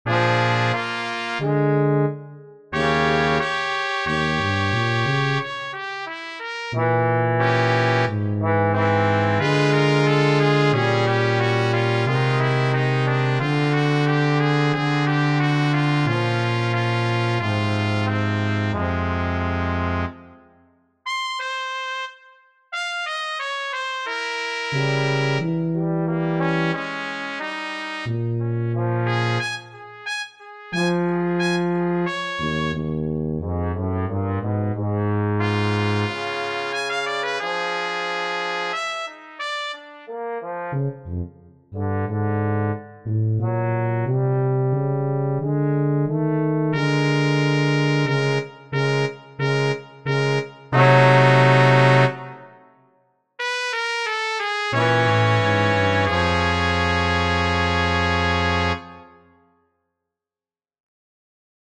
[MP3 Brass quartet]